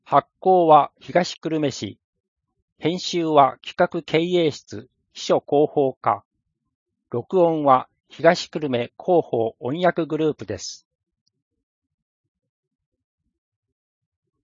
声の広報（平成30年8月1日号）